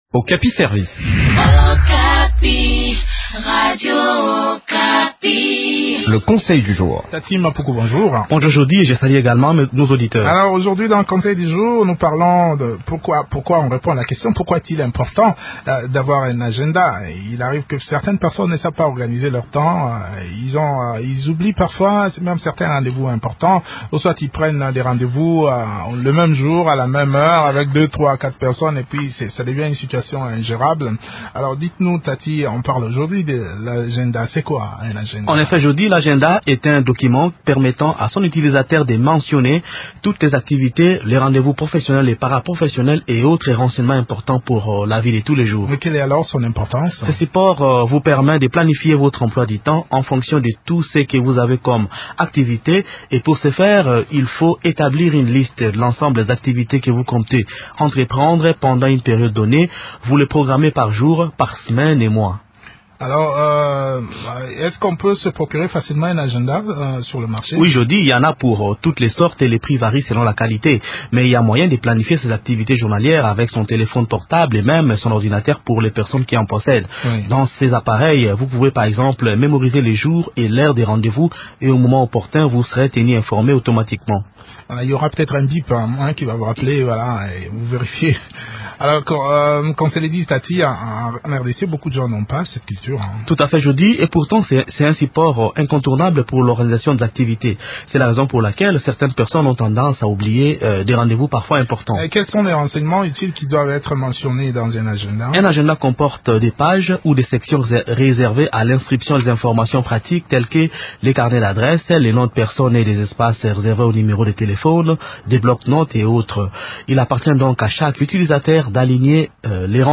Sachez que si vous avez un agenda, vous serez en mesure de bien planifier vos activités de tous les jours. D’autres détails sur l’importance de l’agenda dans cet entretien